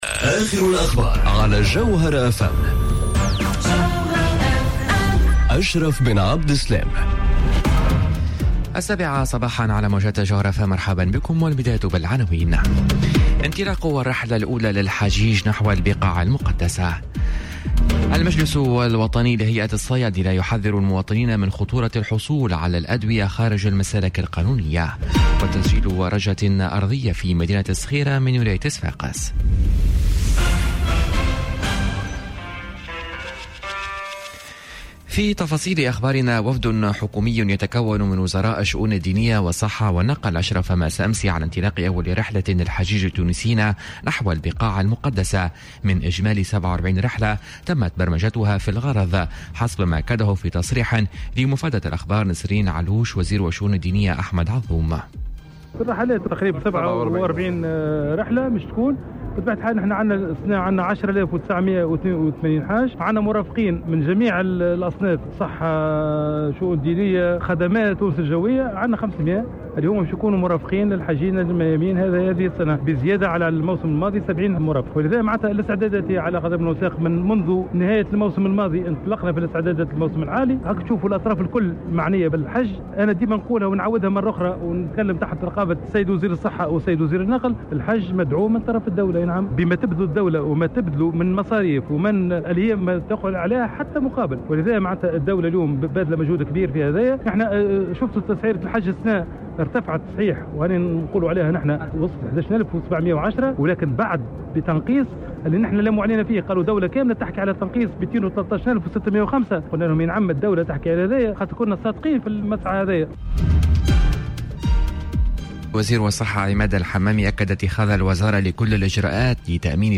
نشرة أخبار السابعة صباحا ليوم الثلاثاء 31 جويلية 2018